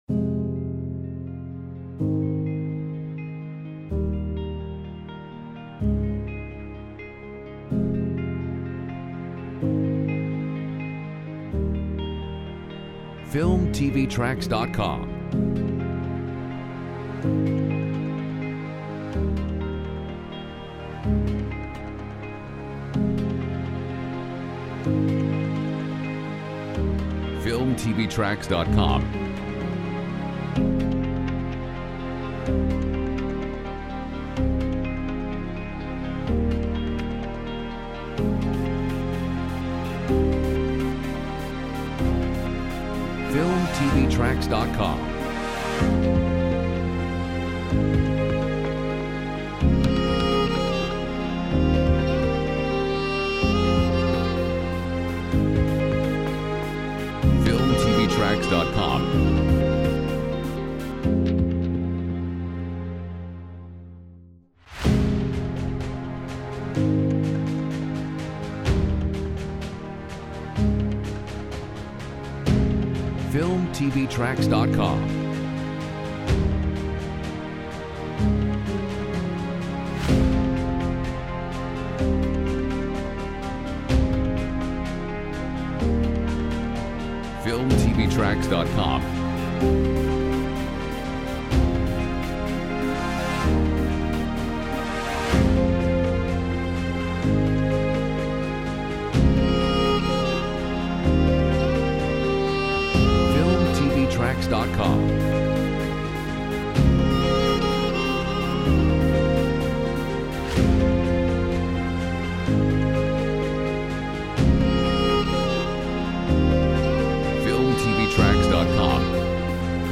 Genres: Piano, Orchestral
Mood: Calm, inspiring, uplifting, emotive